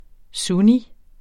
Udtale [ ˈsuni ]